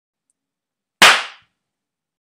Âm thanh người phụ nữ Tát
Đánh nhau, vũ khí 241 lượt xem 04/03/2026
Tải hiệu ứng âm thanh người phụ nữ tát mp3, download woman slap sound effect no copyright mp3 này về tại đây và sử dụng nó cho việc edit video, lồng tiếng cho video của bạn.